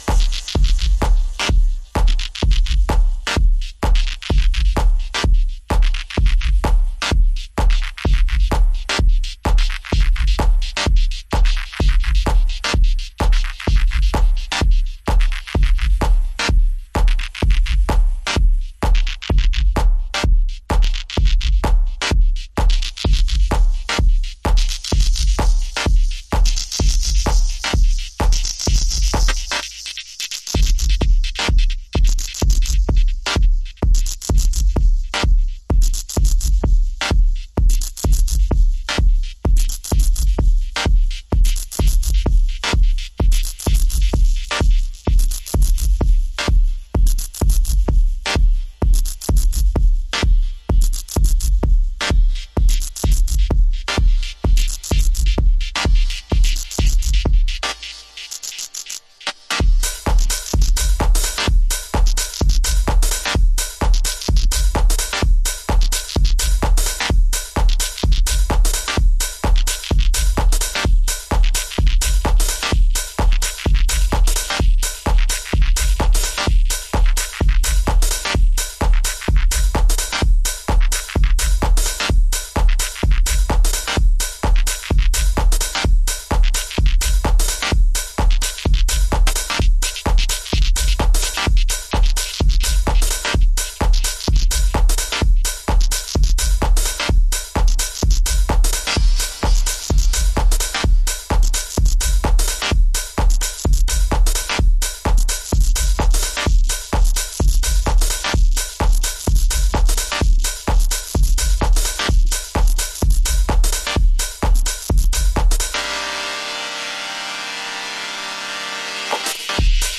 クラシカルテクノ/ミニマルのオイシイところを凝縮したようなアナログ志向のトラック。